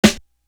Flat Black Snare.wav